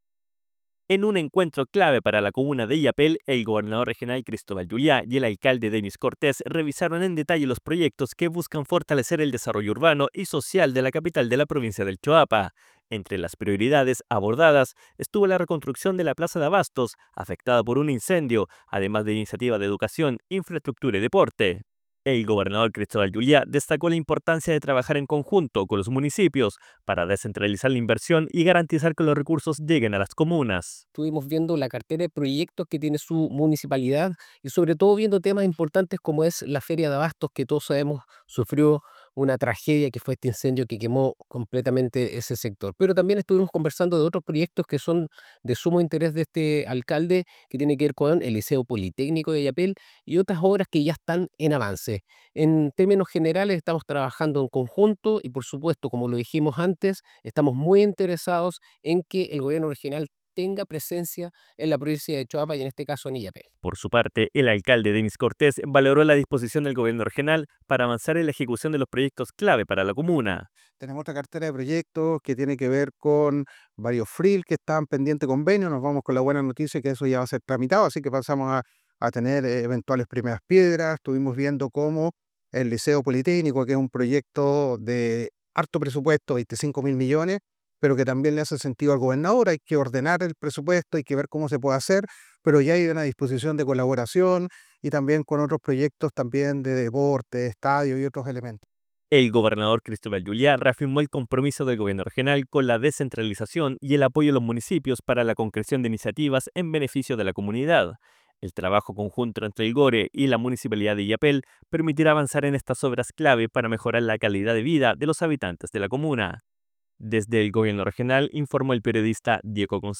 DESPACHO-RADIAL-REUNION-GOBERNADOR-CON-ALCALDE-ILLAPEL.mp3